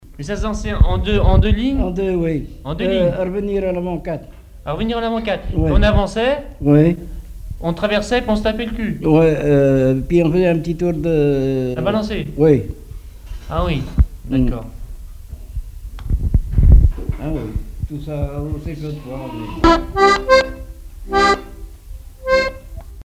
Description de la danse Tape ton cul
Catégorie Témoignage